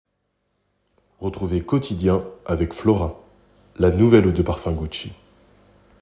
Version 1 posé